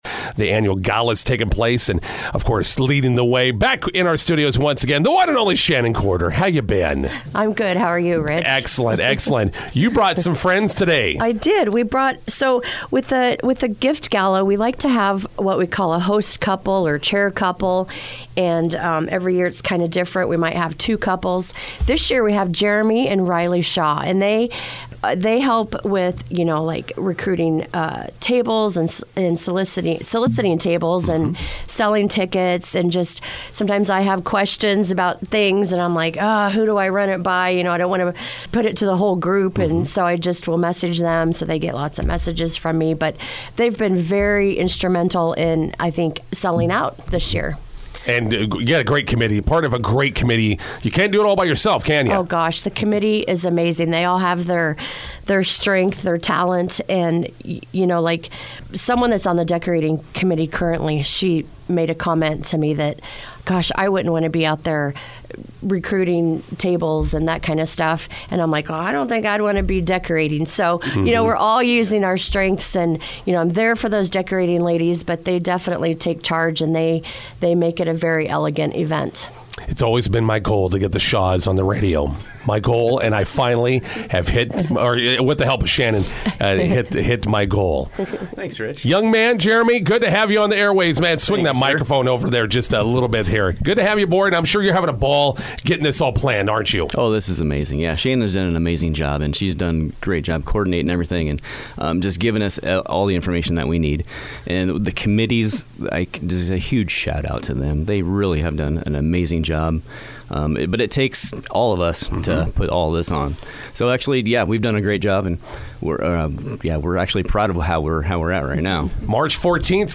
INTERVIEW: McCook St. Patrick’s Church Spring Gala approaching.